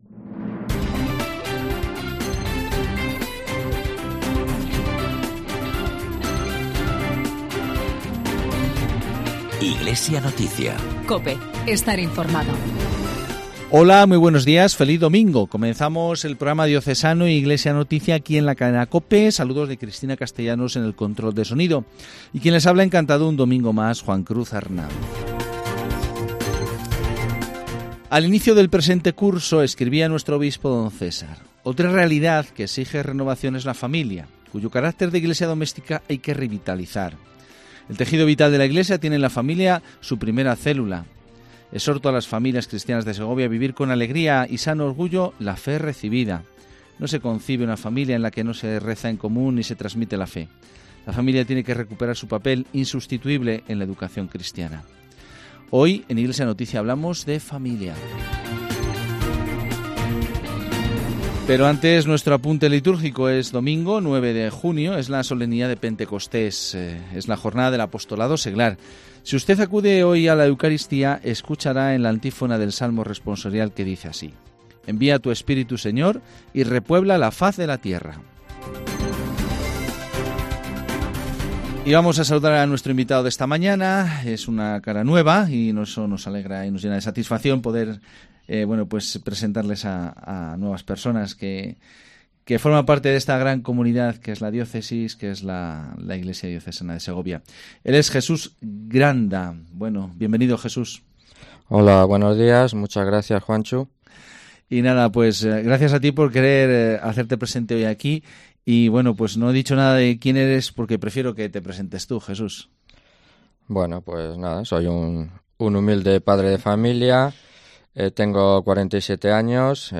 PROGRAMA RELIGIOSO